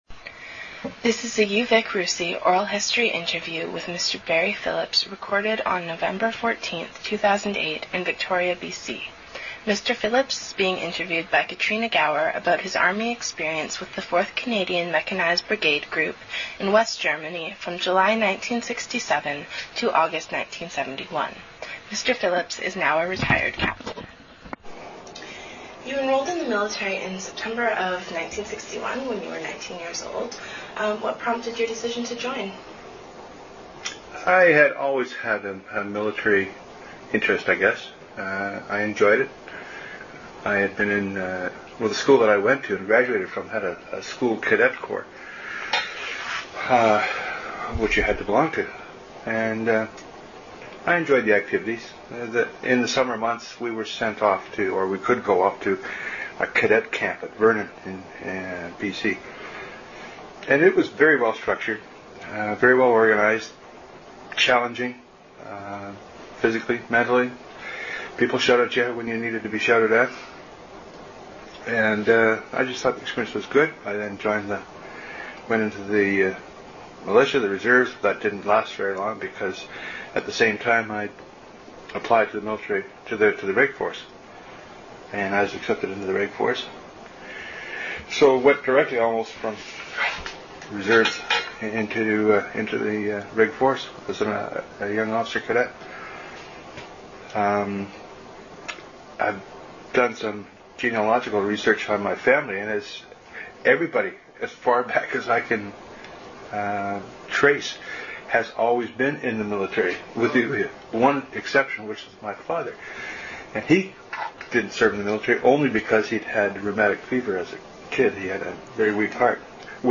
Interview took place on November 14, 2008.